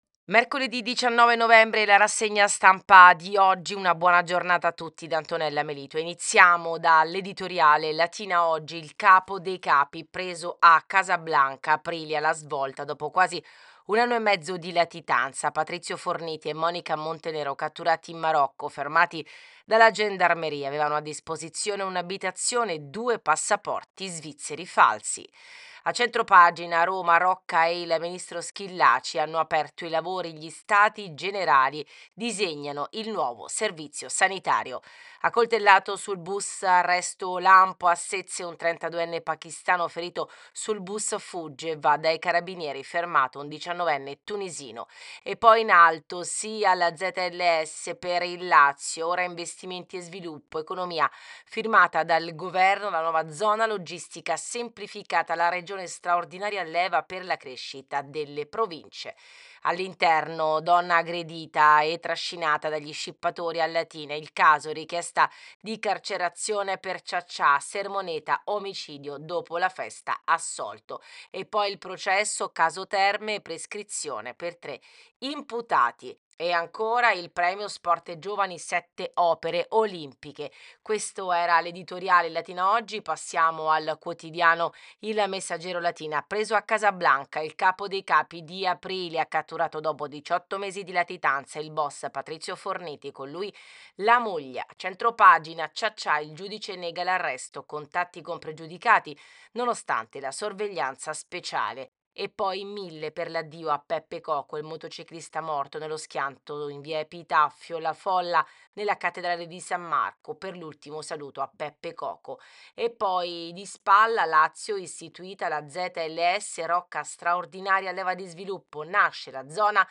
RASSEGNA-STAMPA.mp3